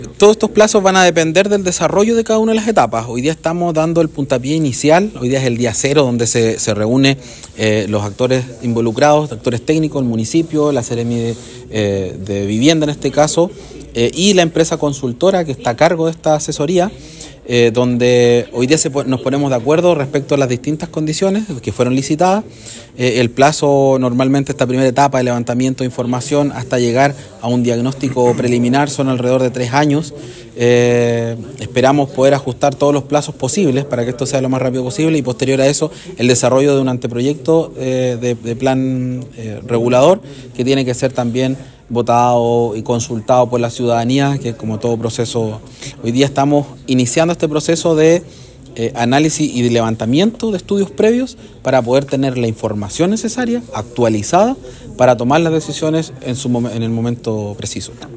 Según indicó el Seremi Fabián Nail, este proceso depende del desarrollo de cada una de las etapas de trabajo por parte de la consultora, de acuerdo a las condiciones que fueron licitadas por las autoridades.